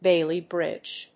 アクセントBáiley brìdge